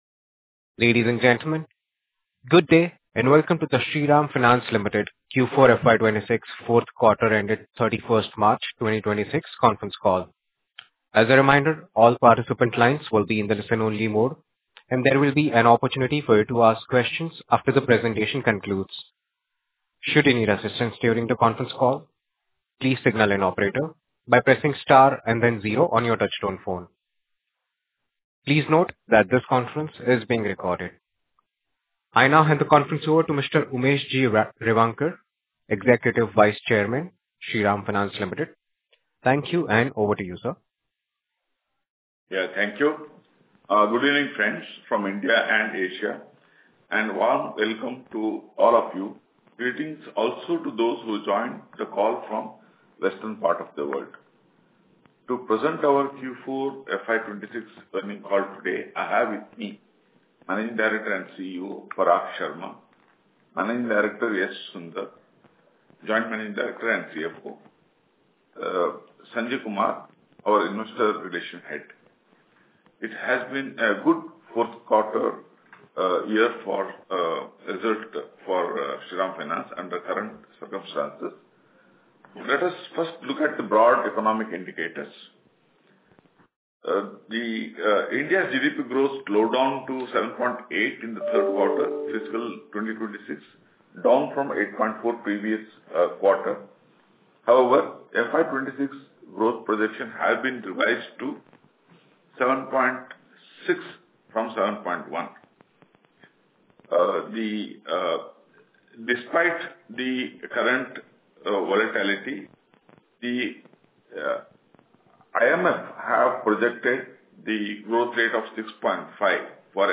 Shriram Finance has officially released the audio recording of its earnings conference call held on April 24, 2026. The session covered the company’s financial performance for the fourth quarter of the financial year 2025-2026 (January-March 2026).